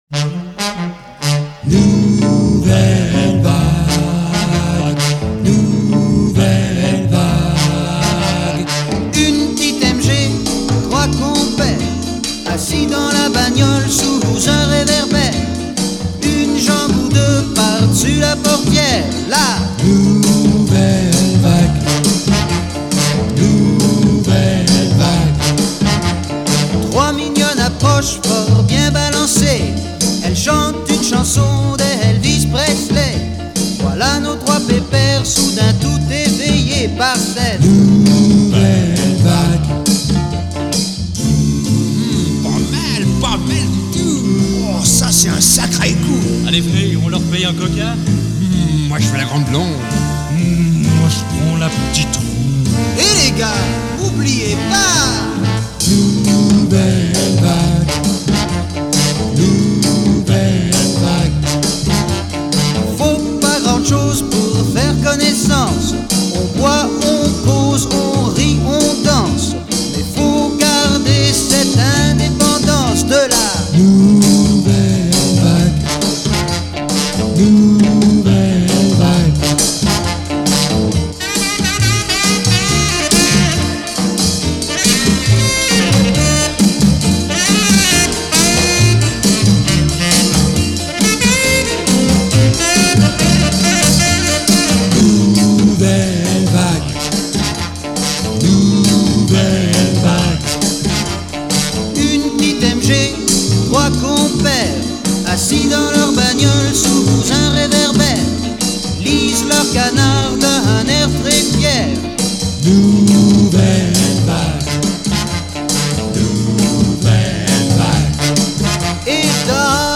Жанр: Bossa Nova.